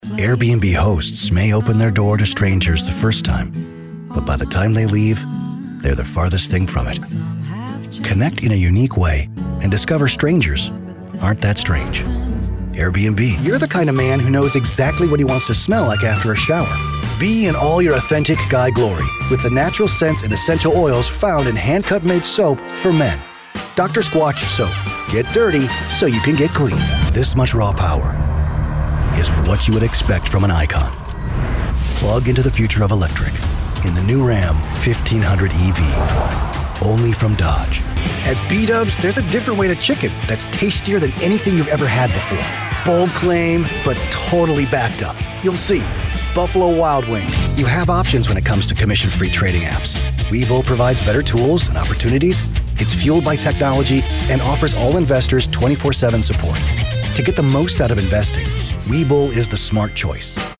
Demo
Teenager, Young Adult, Adult, Mature Adult
Has Own Studio
COMMERCIAL 💸
gravitas
quirky
warm/friendly